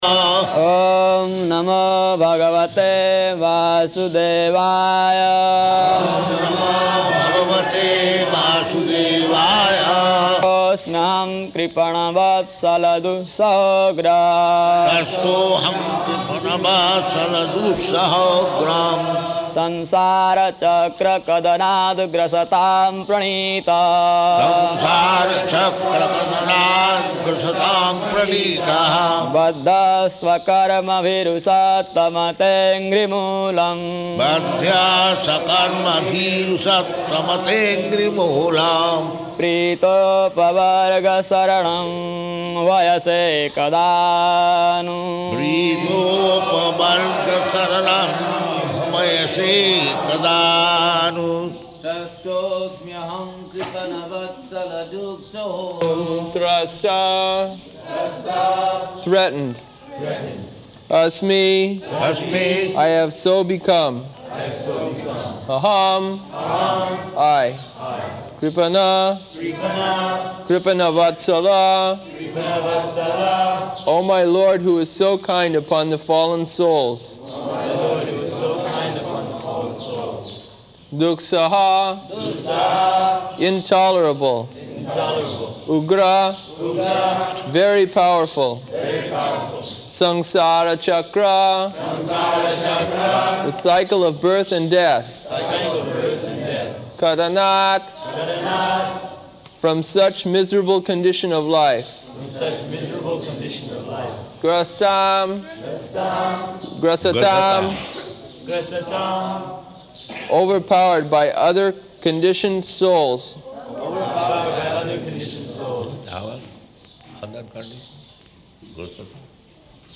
February 23rd 1976 Location: Māyāpur Audio file
[chants verse, Prabhupāda and devotees respond]